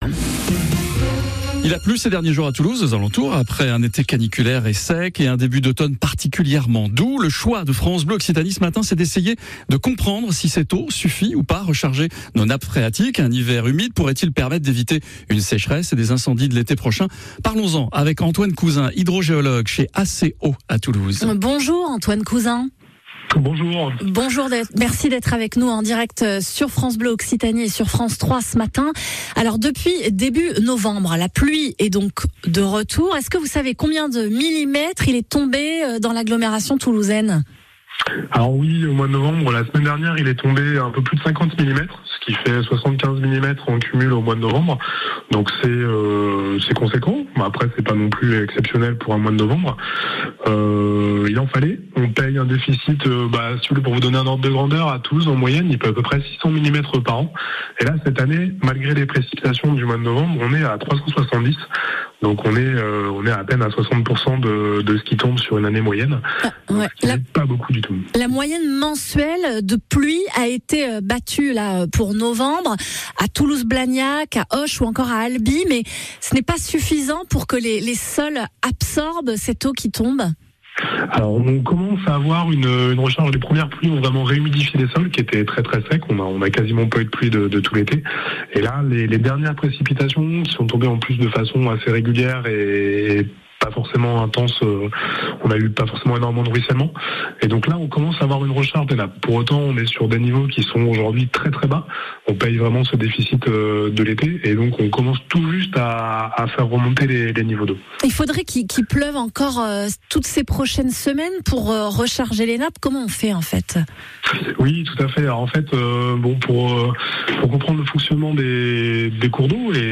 Assistez à l’émission du lundi 28 novembre 2022 à 7h45 et à l’interview d’AC D’EAU par France Bleu Occitanie.